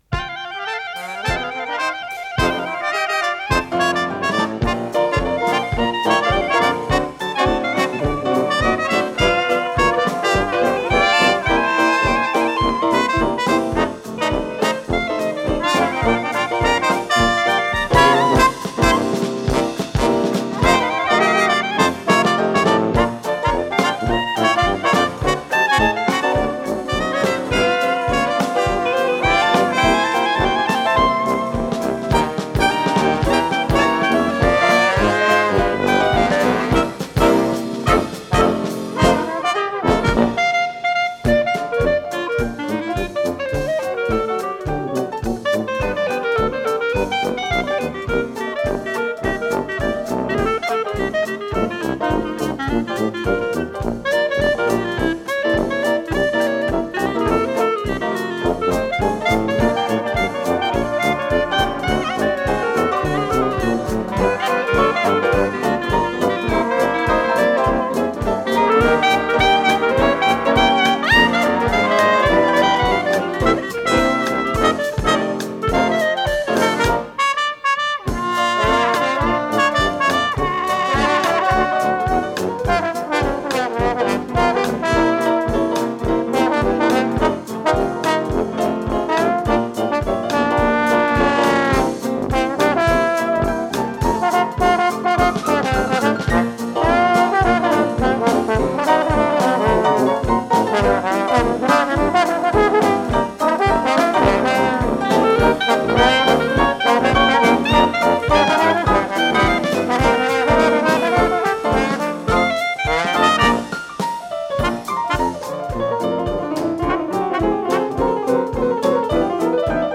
ПодзаголовокПьеса для эстрадного оркестра, си бемоль мажор
Скорость ленты38 см/с
ВариантДубль моно